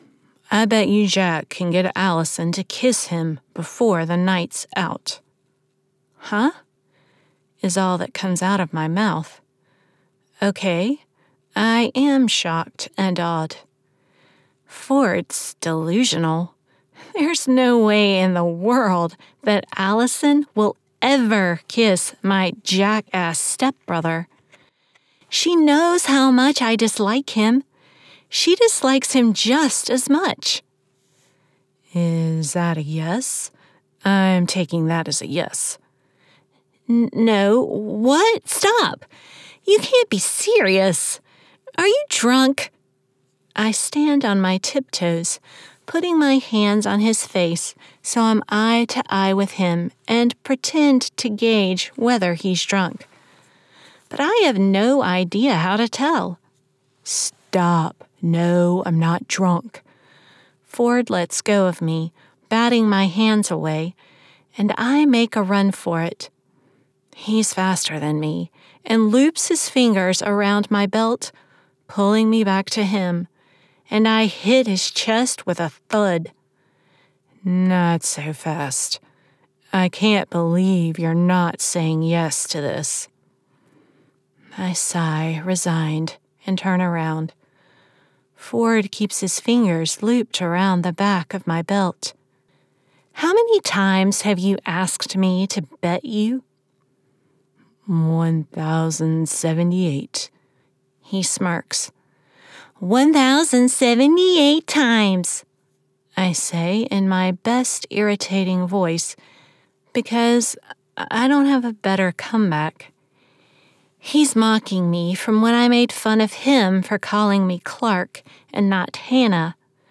A sunny, vivacious, confident voice that conveys professionalism and can tell a great story.
Audiobook Sample
American-General, American-Southern